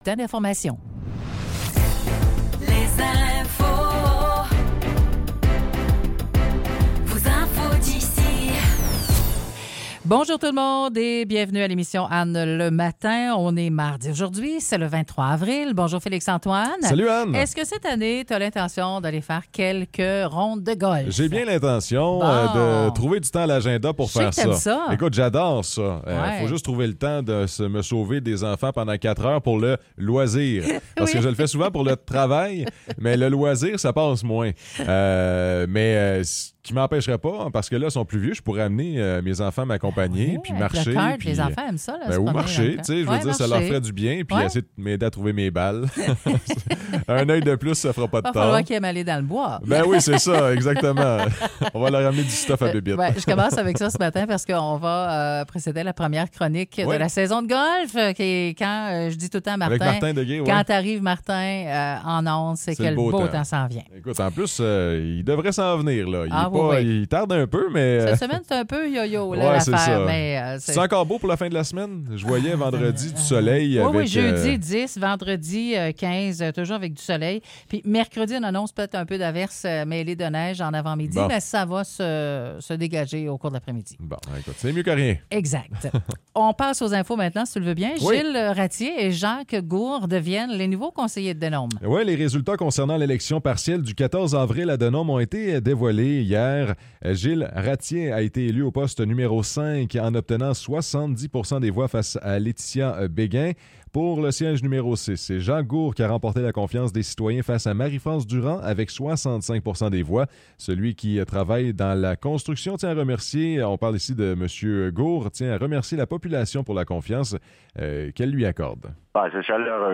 Nouvelles locales - 23 avril 2024 - 9 h